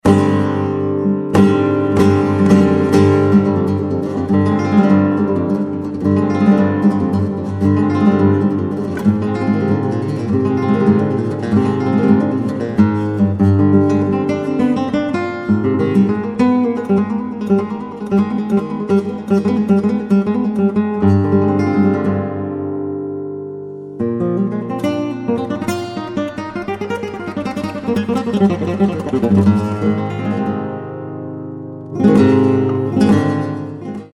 composer, lute & oud player from Japan
Flamenco , Guitar